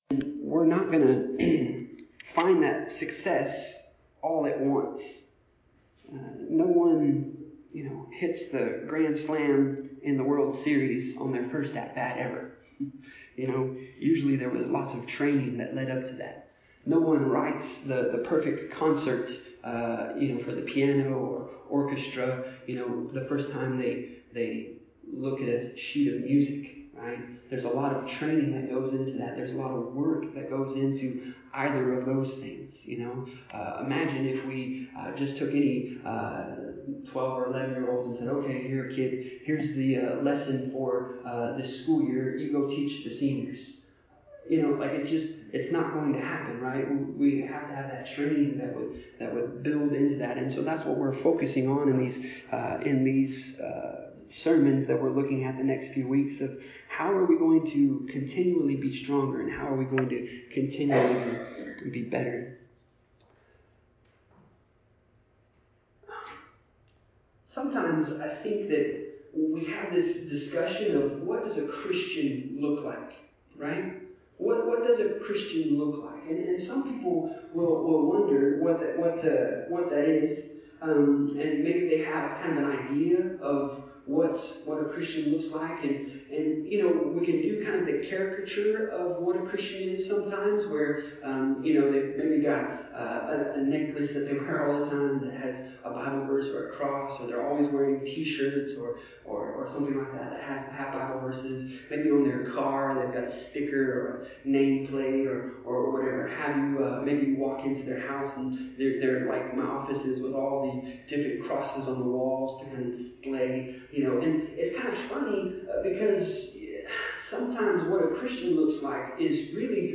The audio recording is from the in-person gathering – recorded live and uploaded at a later date.
Service Type: Sunday Morning